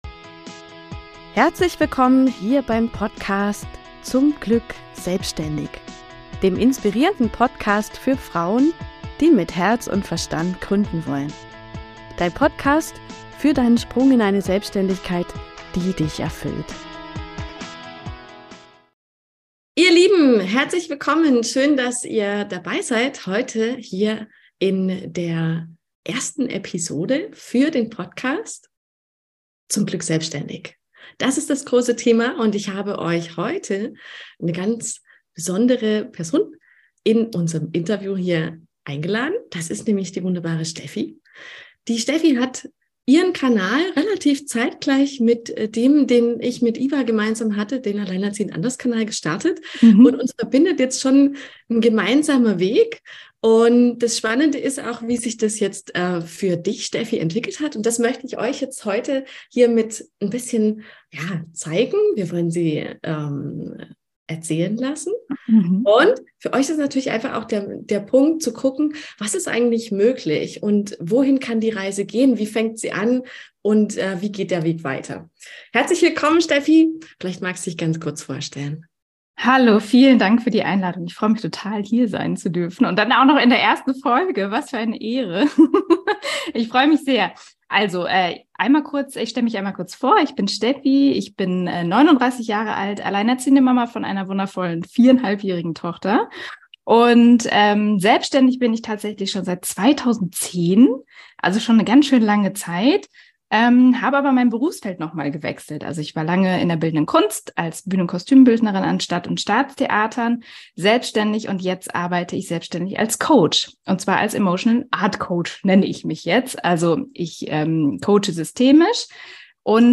Freu dich auf ein mega interessantes Interview, was ziemlich schnell zu einer Talkrunde geworden ist...